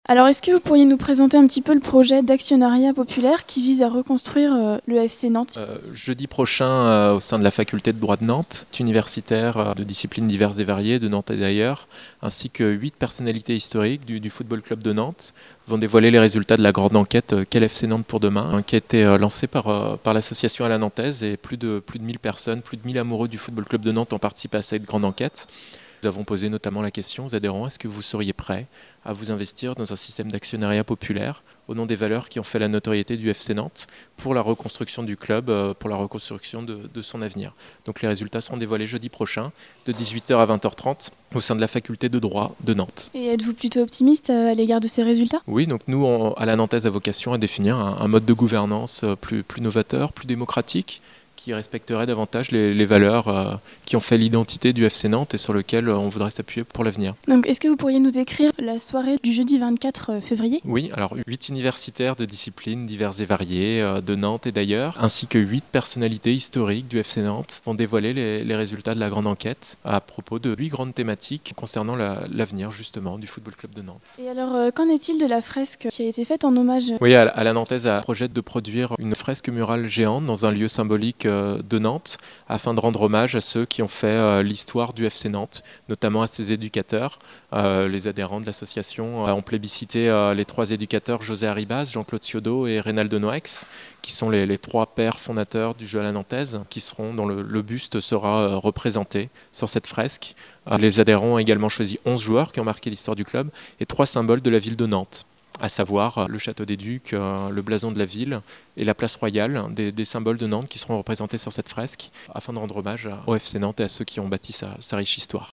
Reportage / documentaire